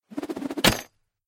Звуки ниндзя
Шум летящего шурикена ниндзя